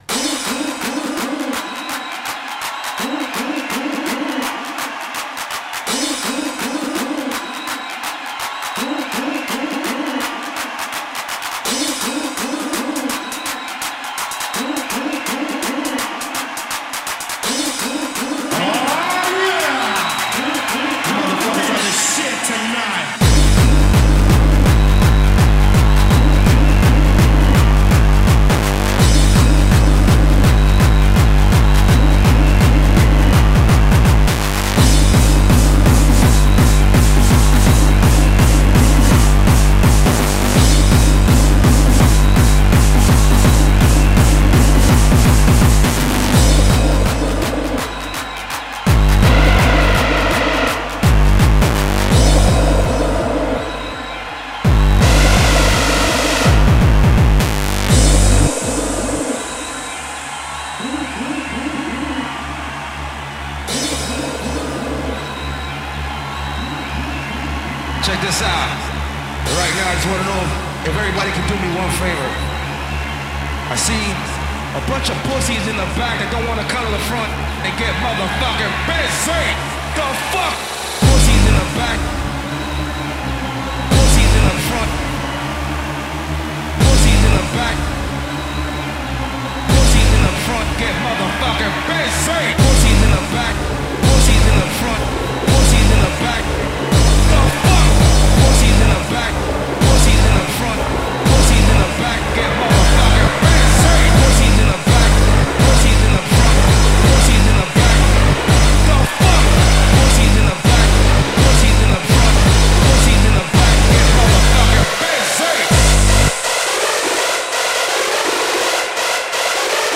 Genre: Hardcore.